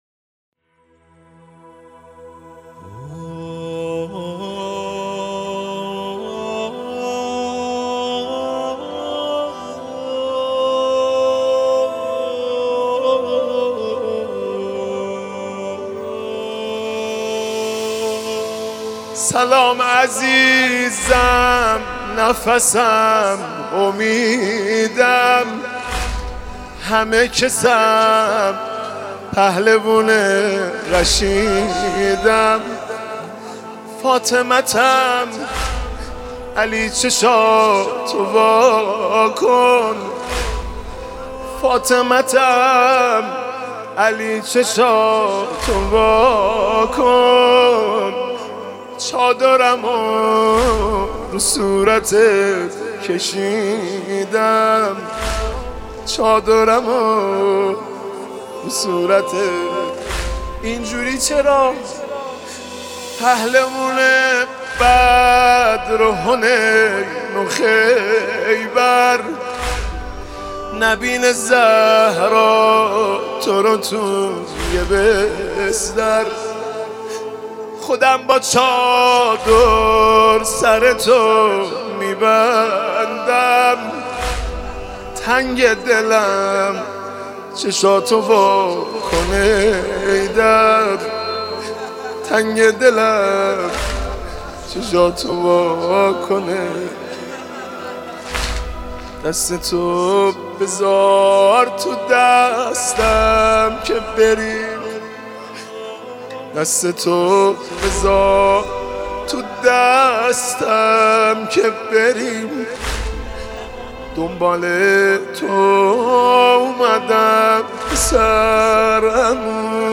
مداحی شهادت امام علی